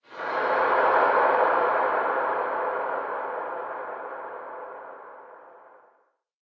minecraft / sounds / ambient / cave
cave4.ogg